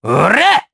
Neraxis-Vox_Attack2_jp.wav